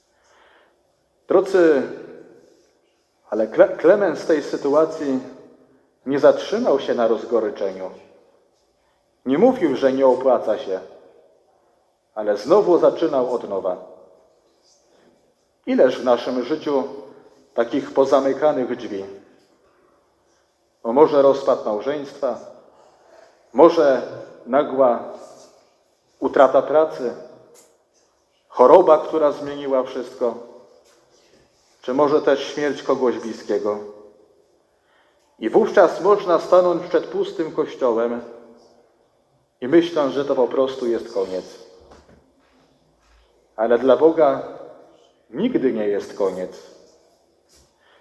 fragmenty homilii audio: